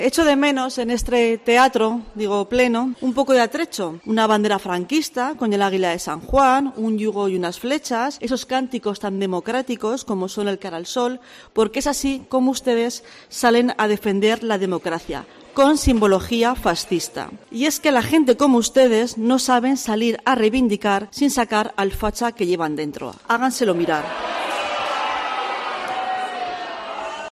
Elena Tomás (ZEC) durante su intervención en el pleno extraordinario sobre la Ley de Amnistía